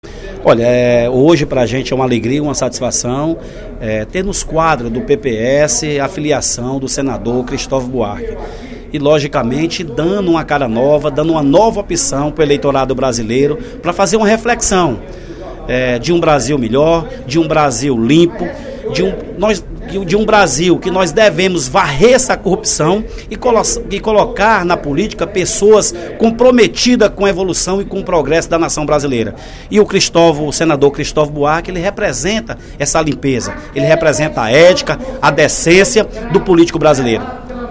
O deputado Tomaz Holanda (PPS) destacou, durante o primeiro expediente da sessão plenária desta quarta-feira (17/02),  a filiação do senador Cristovam Buarque ao PPS.